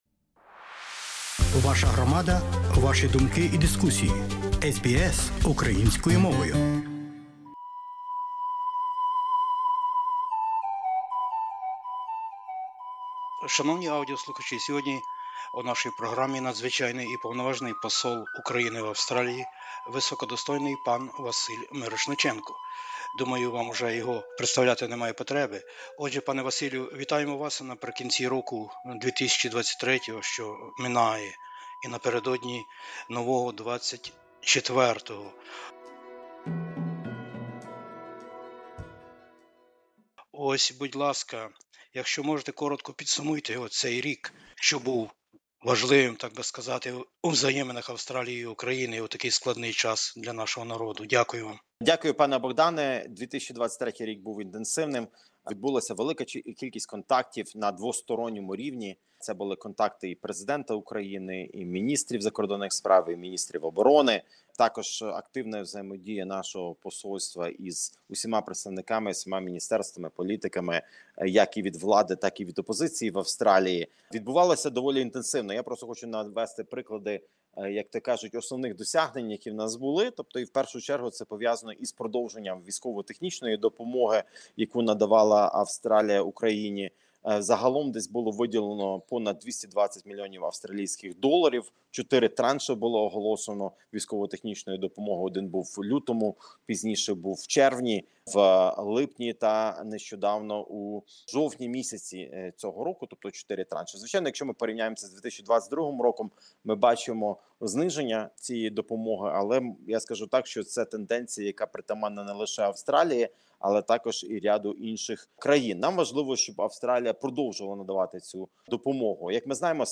Війна в Україні та допомога Австралії. Надзвичайний і Повноважний Посол України в Австралії високодостойний Василь Мирошниченко - в гостях в аудіослухачів SBS Ukrainian.